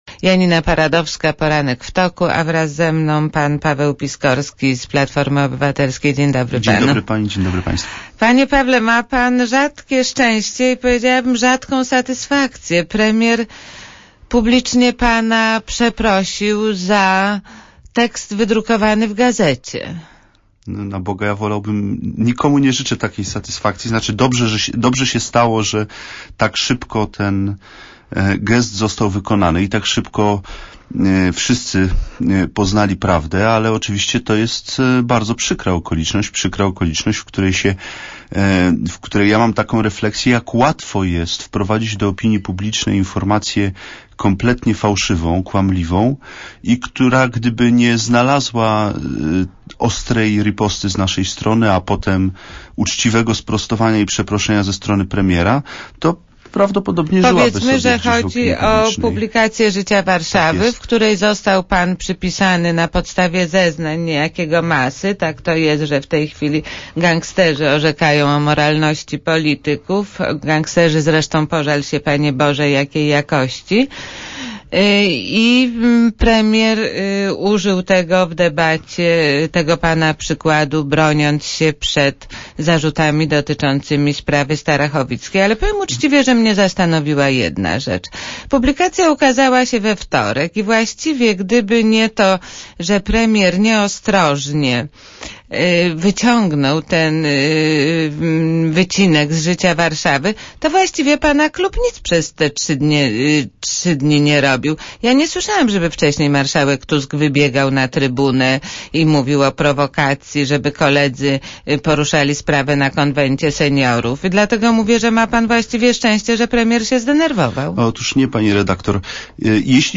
Rozmowa z Pawłem Piskorskim - 3.6MB